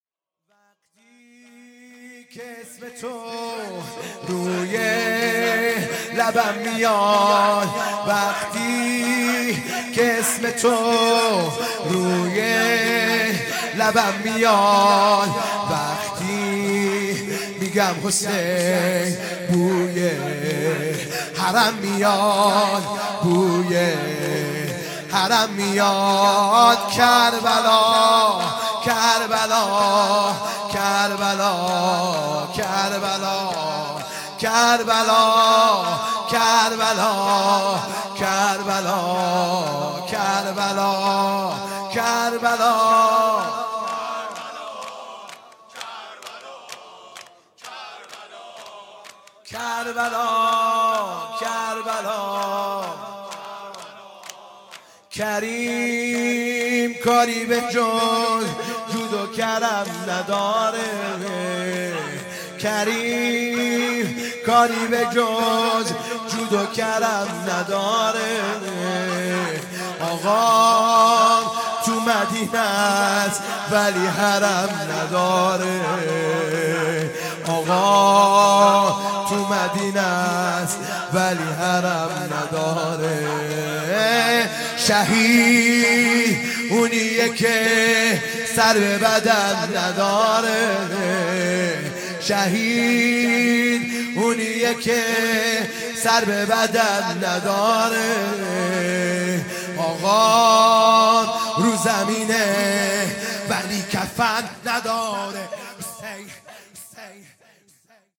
شور
وقتی که اسم تو|شهادت امام حسن (ع) ۸ آذر ۹۵